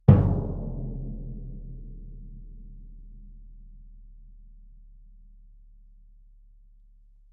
bdrum3-fff-rr1.mp3